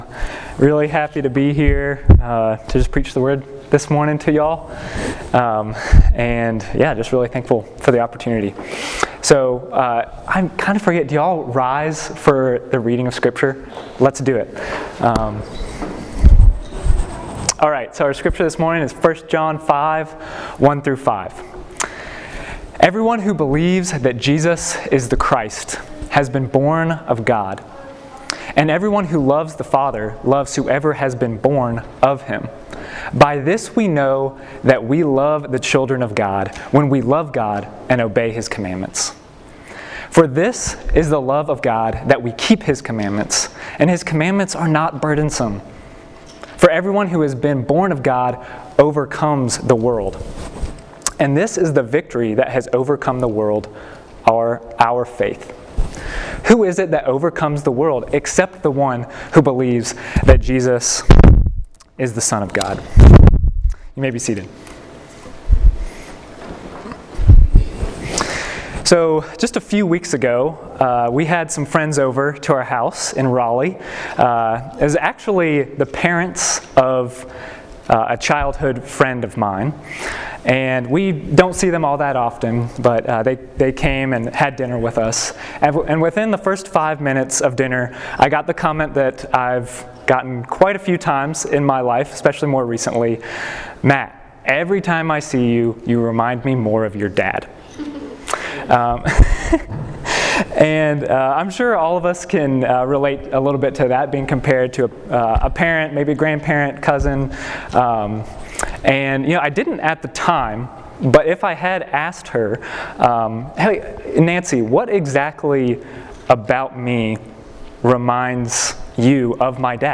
Sermon, “Christian Birthmarks” , Nov 7, 2021